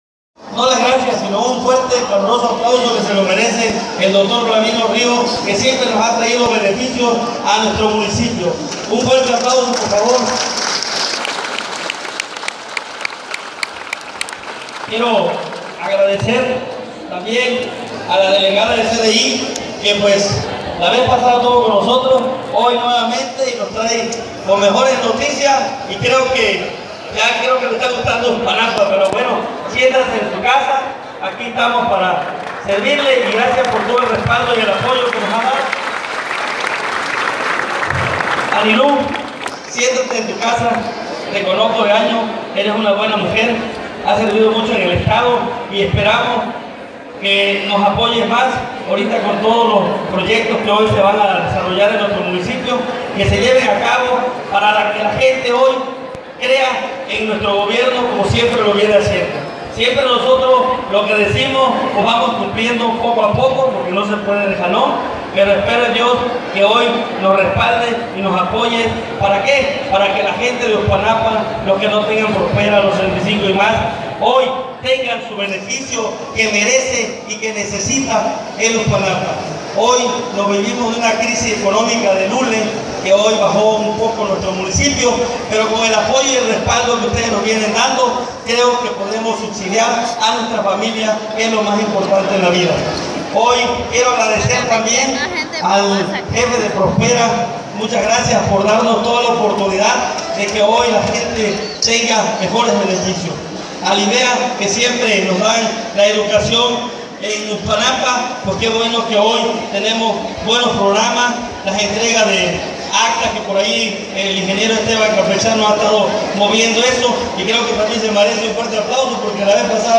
Nada mas no engañar, pide Pablo Prieto Morales al Secretario de Gobierno, en la Feria de Apoyos y Servicios Institucionales.
Audio Integro de la Intervención del Alcalde Pablo Prieto Morales, de Uxpanapa Veracruz.